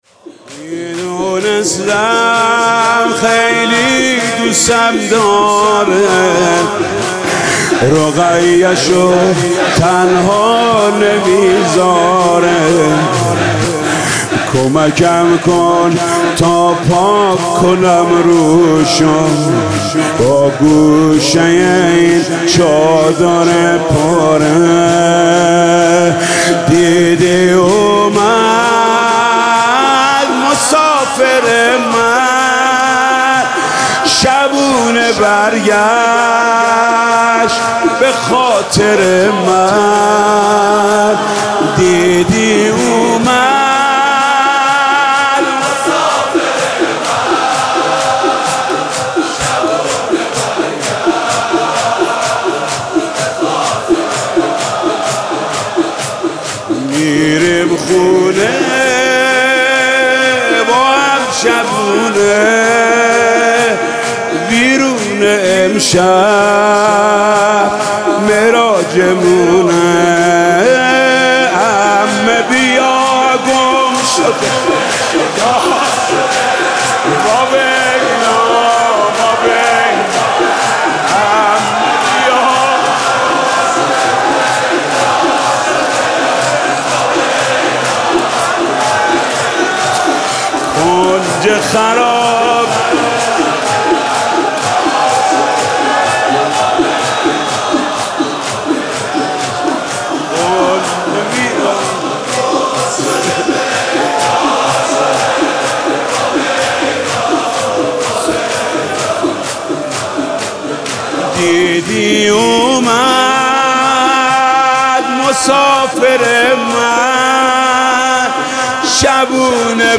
برگزاری مراسم محرم حسینی 1401